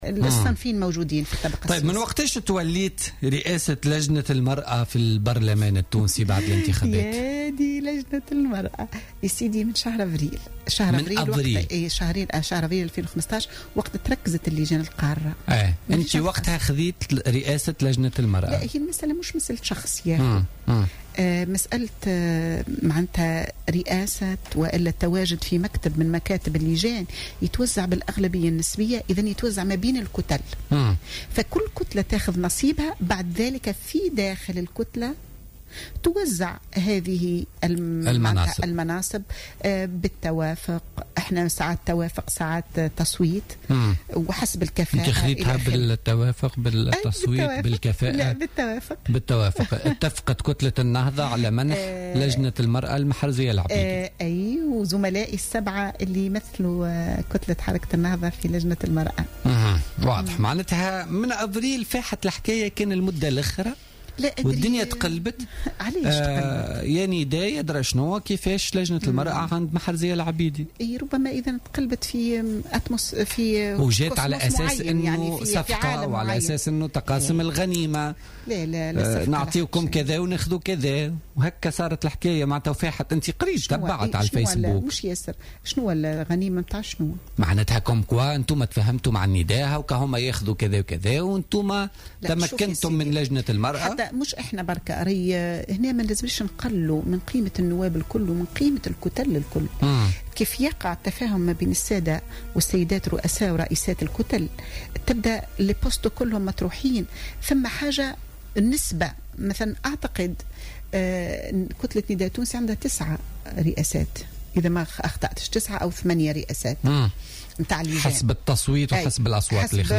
واعتبرت العبيدي، خلال حصة بوليتيكا اليوم الاربعاء، أن الاختلاف في الأفكار بين الطرفين لا يمكن أن يفسد للود قضية، من منطلق الإحترام والوطنية، متوقعة أن يعود التنافس بين الطرفين في الاستحقاقات الانتخابية القادمة بما تقتضيه الديمقراطية.